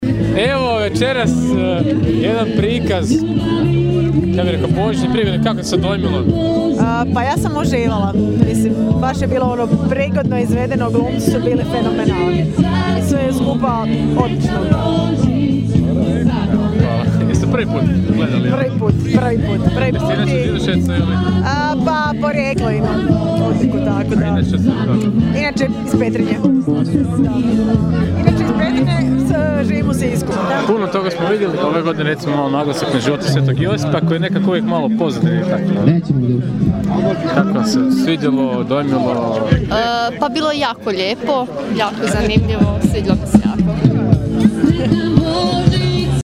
Prikaz su gledale dvije prijateljice iz Siska: